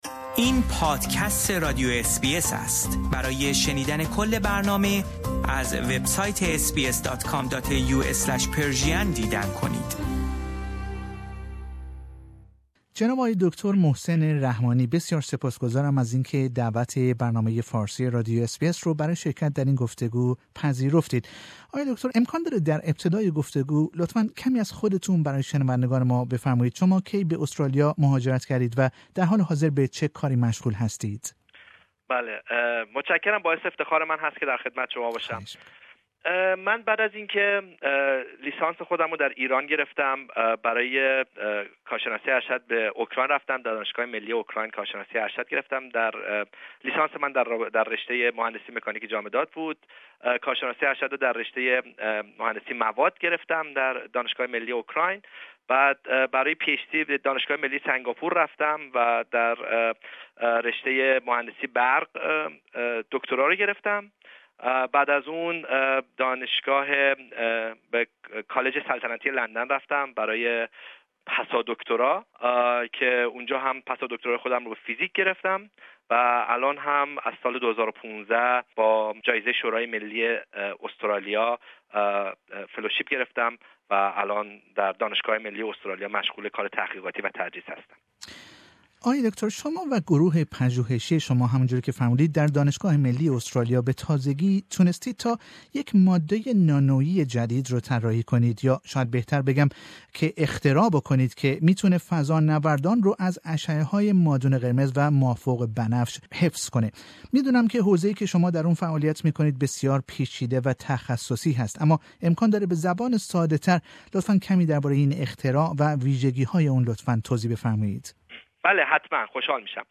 این دانشمند ایرانی به همراه گروه پژوهشی خود یک نوع ورقه بسیار نازک نانویی را اختراع کرده که می تواند فضانوردان را از اشعه های مادون قرمز و ما فوق بنفش حفظ کند. او در گفتگو با بخش فارسی رادیو اس بی اس درباره این اختراع که می تواند در بسیاری از صنایع دیگر نیز مورد استفاده قرار گیرد، سخن می گوید.